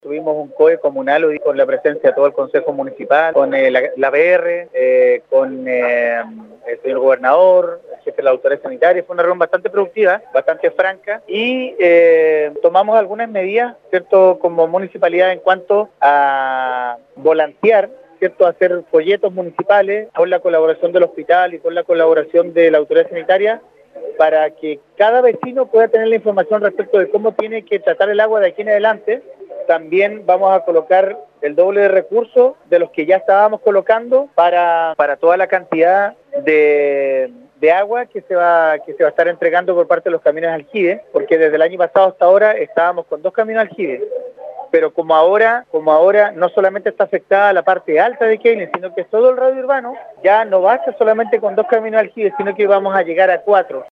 El alcalde de Queilen, Marcos Vargas, señaló que la situación se ha tornado muy difícil, luego de la resolución de la seremi de Salud que extendió la restricción para la todo el poblado de Queilen.